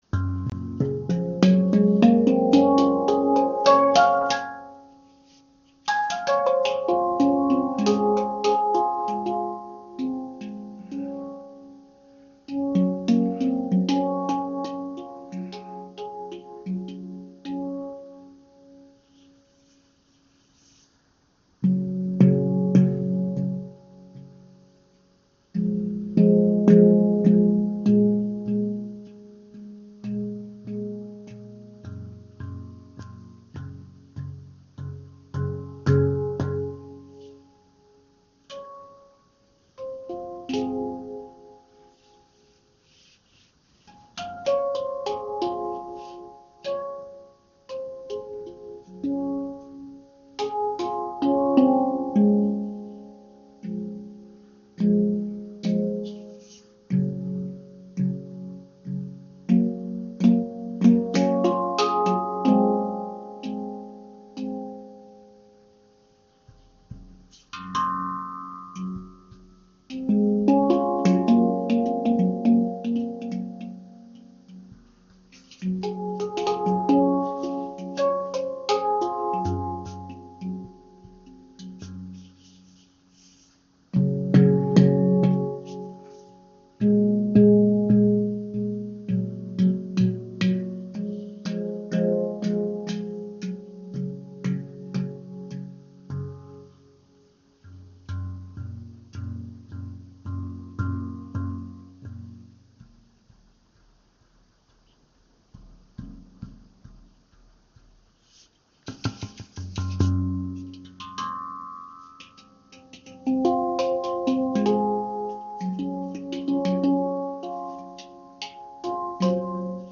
Klangbeispiel
Alle Klangfelder sind sehr gut gestimmt und lassen sich auch mit fortgeschrittenen Spieltechniken anspielen.